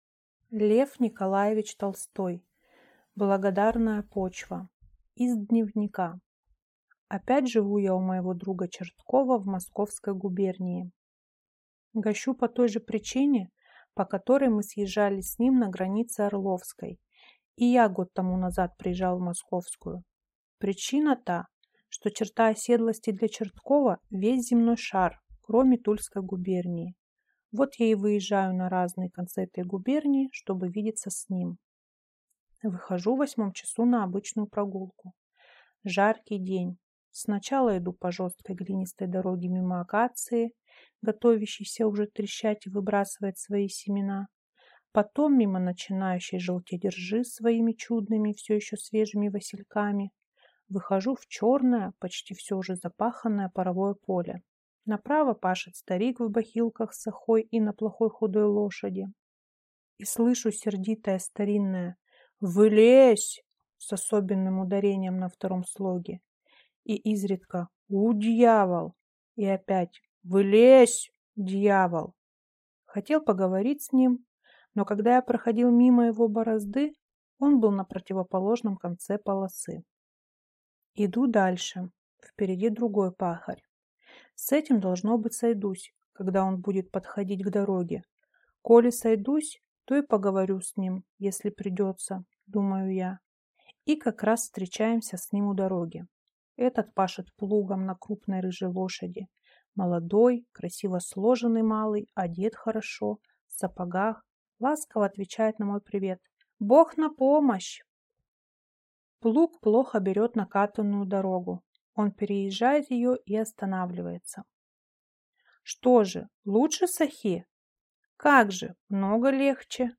Аудиокнига Благодарная почва | Библиотека аудиокниг